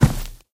sounds / material / human / step / new_wood3.ogg
new_wood3.ogg